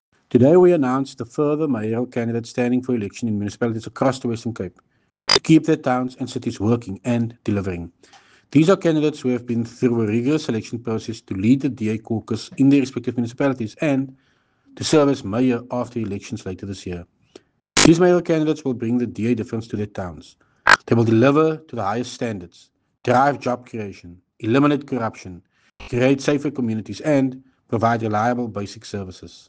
Issued by Tertuis Simmers – DA Western Cape
Note to editors: Please find attached soundbite in